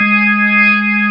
55o-org11-G#3.aif